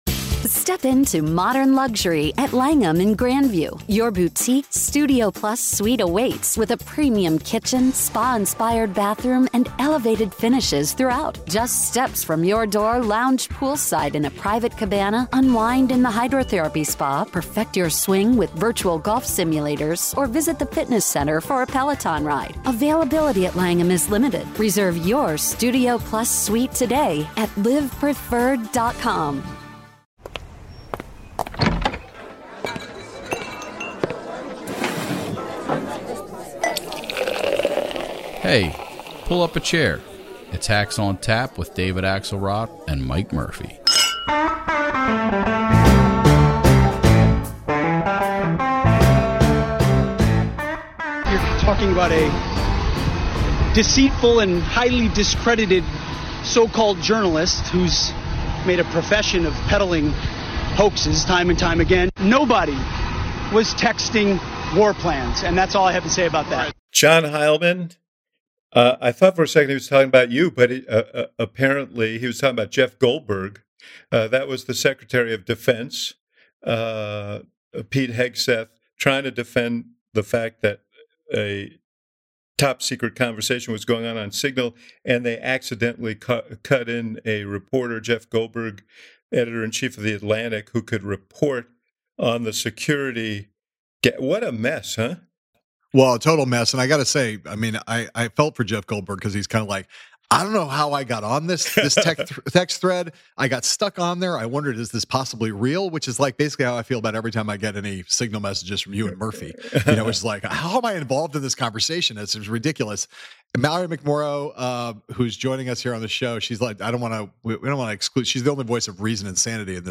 This week, Axe and Heilemann are joined by Michigan State Senator Mallory McMorrow for a deep dive into the week’s political mayhem.